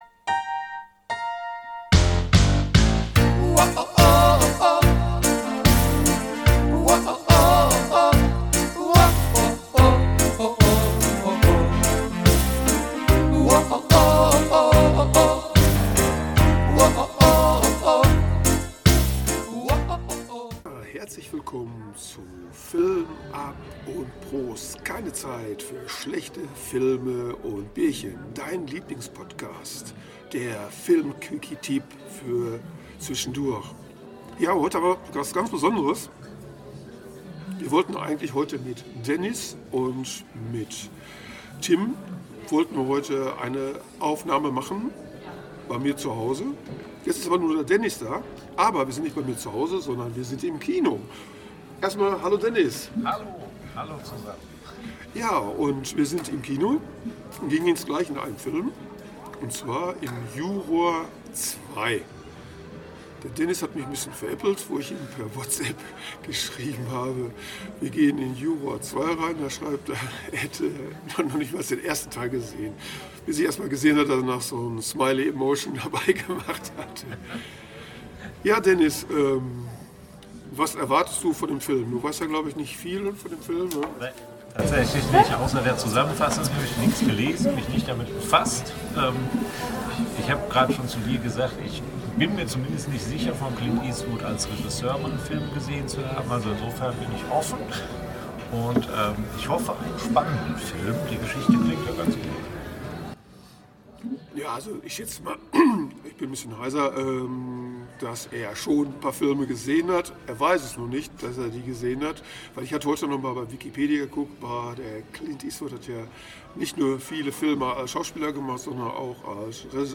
Der Filmquickie - Tipp mit Ruhrpottcharme und lecker Bierchen